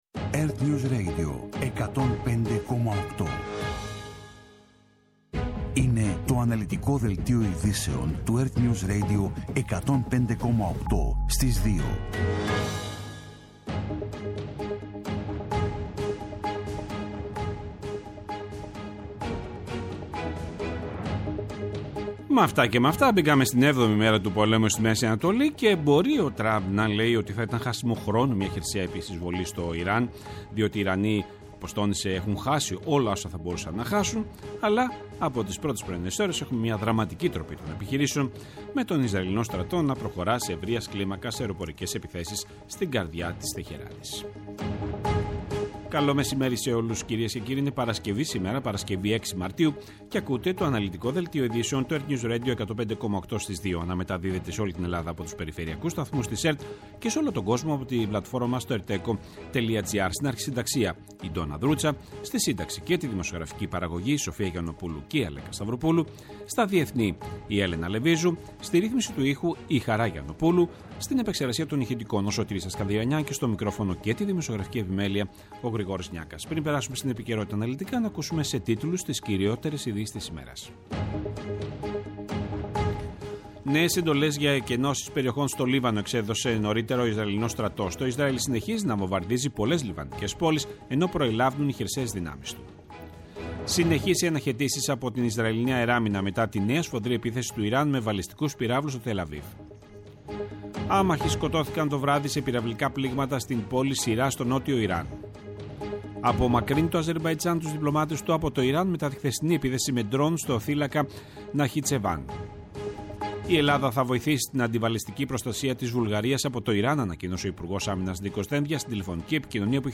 Το αναλυτικό ενημερωτικό μαγκαζίνο στις 14:00.
Με το μεγαλύτερο δίκτυο ανταποκριτών σε όλη τη χώρα, αναλυτικά ρεπορτάζ και συνεντεύξεις επικαιρότητας.